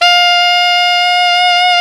SAX ALTOMF0L.wav